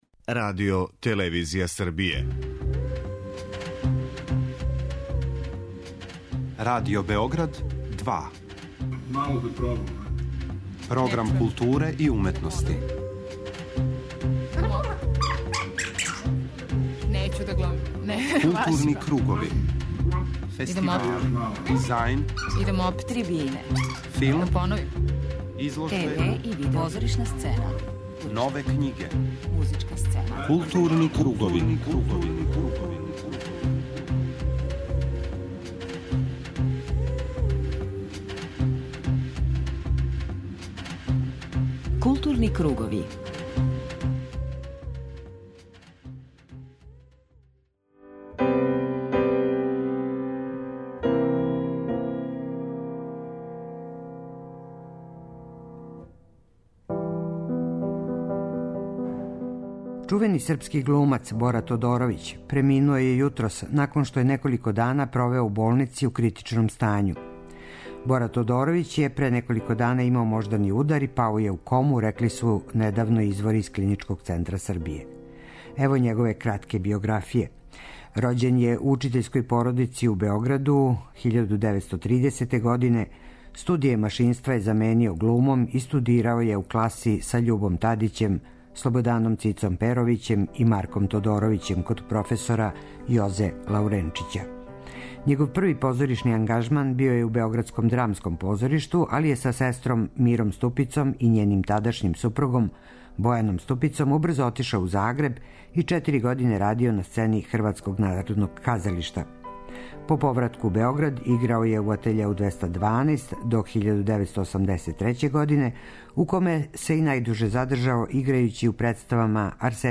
преузми : 53.71 MB Културни кругови Autor: Група аутора Централна културно-уметничка емисија Радио Београда 2.